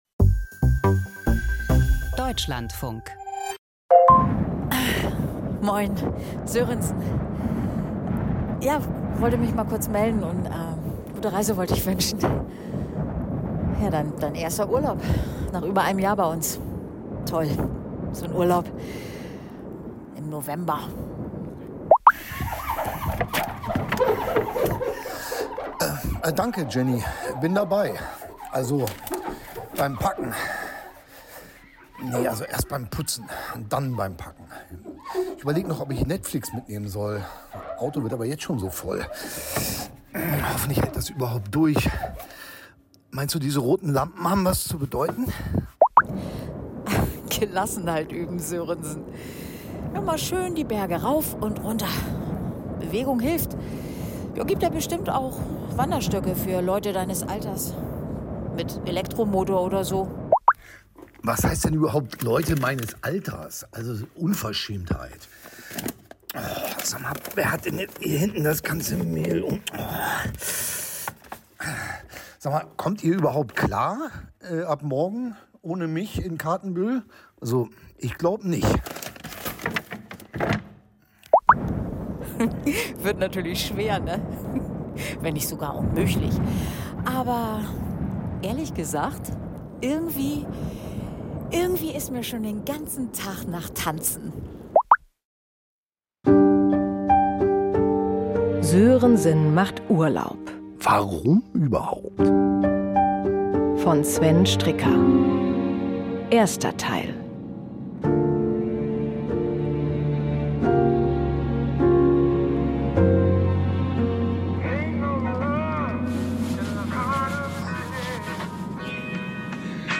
Sörensen macht Urlaub (1/2) – Krimi-Hörspiel mit Bjarne Mädel
Krimi-Hörspiel mit Bjarne Mädel.